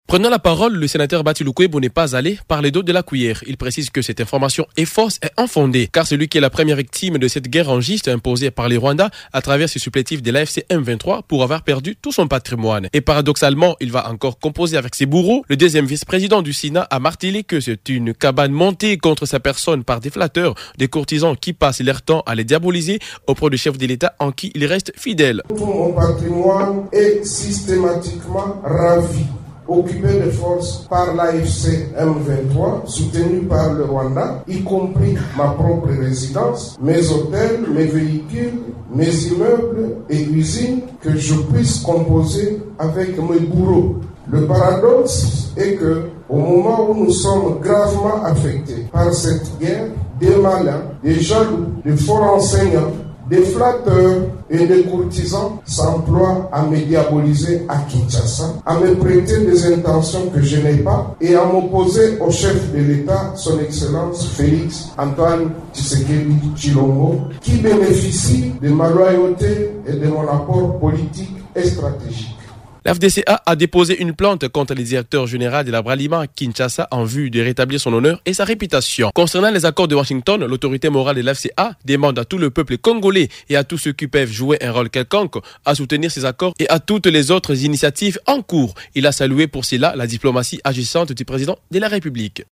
Il a exprimé sa position lors d’une conférence de presse tenue mercredi 4 mars à Kinshasa.
Vous pouvez suivre un extrait du discours de Bahati Lukwebo: